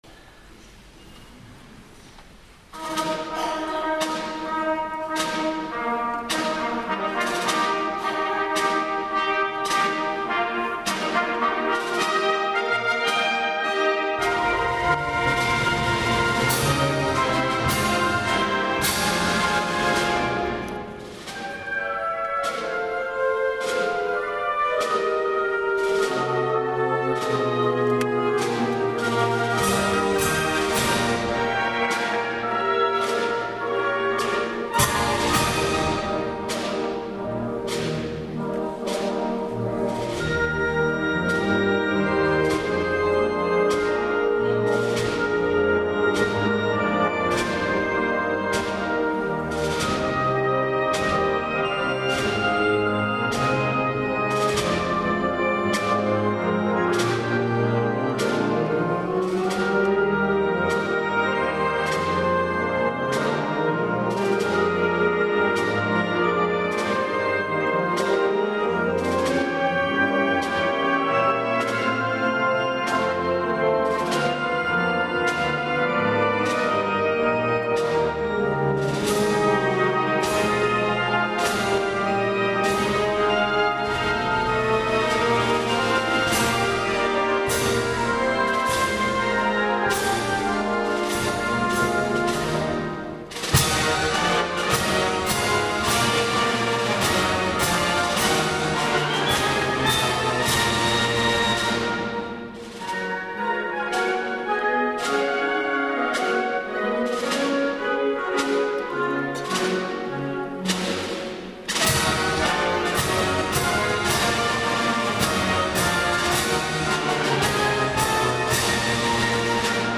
una marcha
En la jornada de hoy podemos ofrecerles la grabación de esta pieza que fue reestrenada por la Municipal de Sevilla dentro del Pregón del Colegio de Peritos Industriales de Sevilla en la Iglesia de la Magdalena pinchando